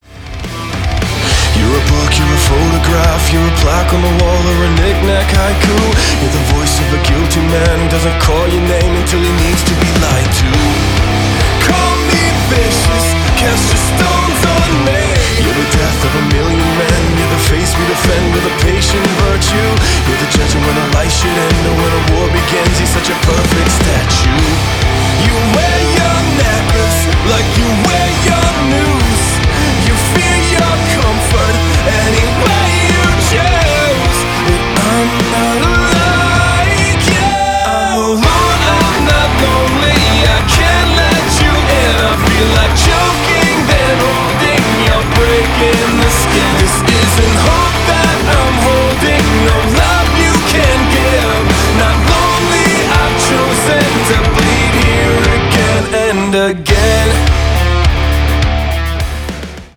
Kategória: Rock